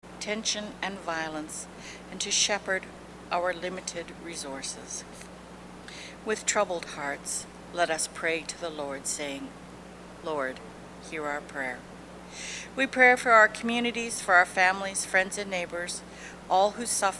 Sermons | St. Marys' Metchosin